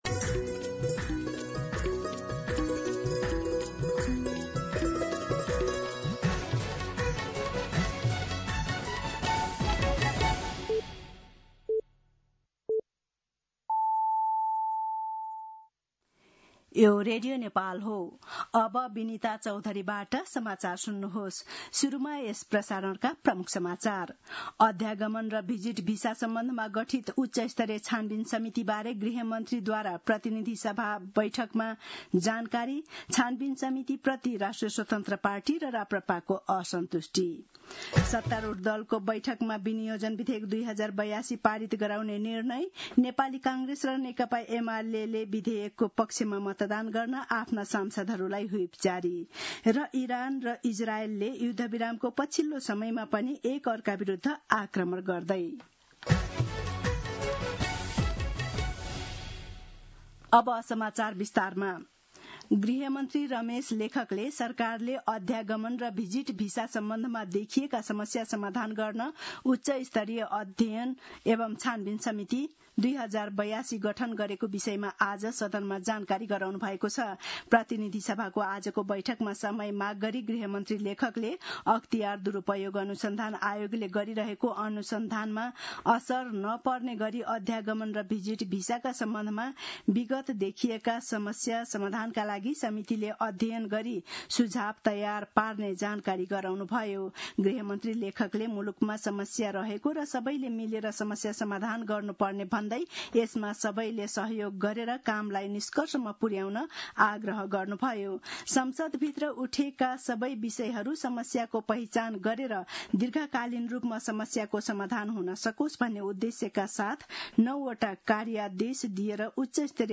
दिउँसो ३ बजेको नेपाली समाचार : १० असार , २०८२
3pm-News-10.mp3